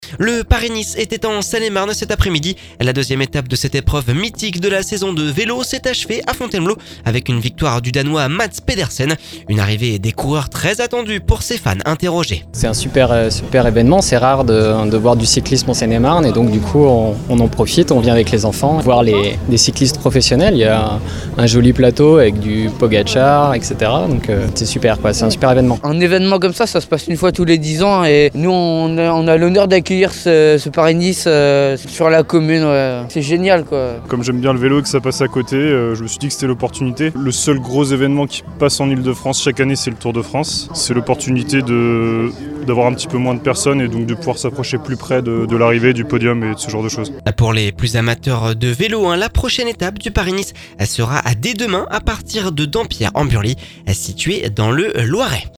La deuxième étape de cette épreuve mythique de la saison de vélo s’est achevée à Fontainebleau avec une victoire du danois Mads Pedersen. Une arrivée des coureurs très attendue pour ces fans intérrogés…Prochaine étape du Paris-Nice demain à partir de Dampierre-en-Burly dans le Loiret !